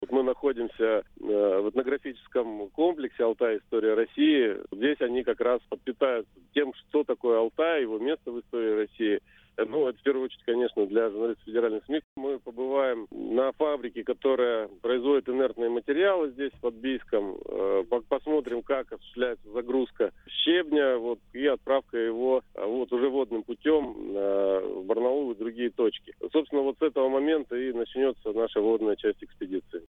Начальник регионального управления по печати и массовым коммуникациям Евгений Нечепуренко рассказал, где сейчас находятся путешественники, а также с чего начнётся самая интересная часть маршрута: